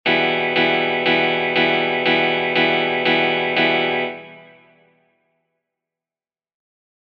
Viertel Note Abschlag
Wir greifen einen E-Moll Akkord und schlagen die Gitarrensaiten vier Mal ab. mit Jedem Abschlag zählen wir einmal.